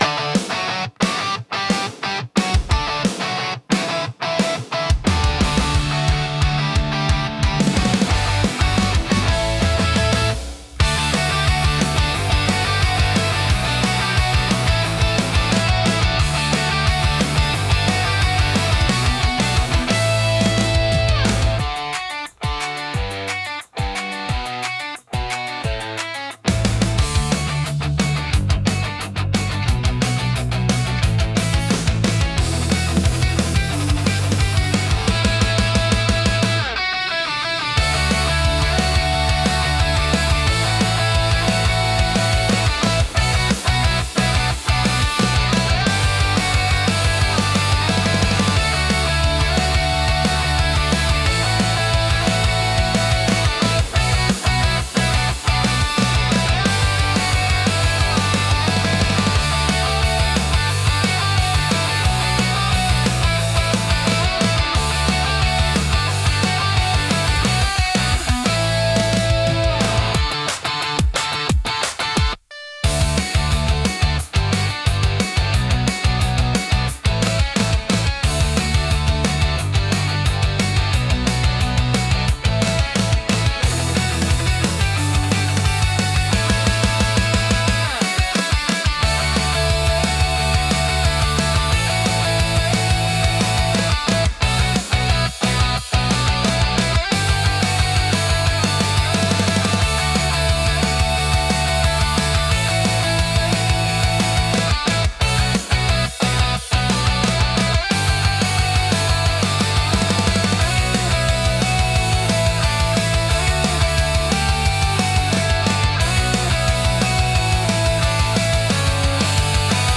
生々しさと疾走感を併せ持つ力強いジャンルです。